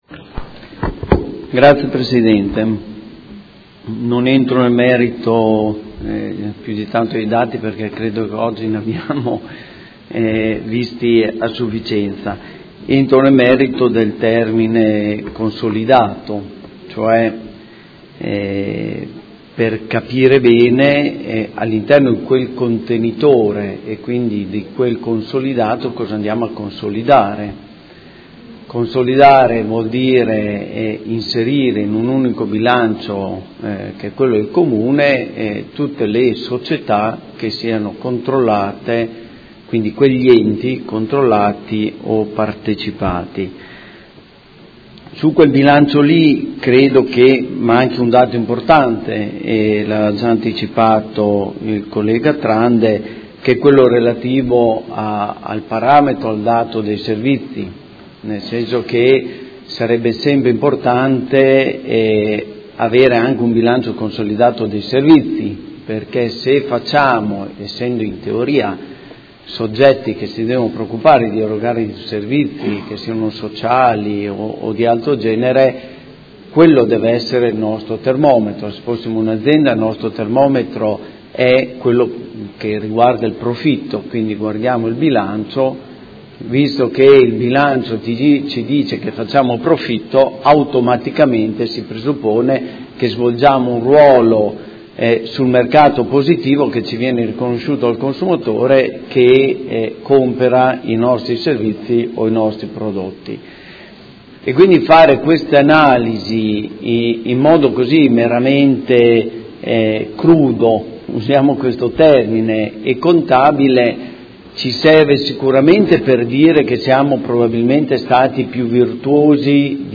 Seduta del 22/09/2016 Proposta di deliberazione: Bilancio Consolidato 2015 del Gruppo Comune di Modena, verifica finale del controllo sulle Società partecipate per l’esercizio 2015 e monitoraggio infrannuale 2016. Dibattito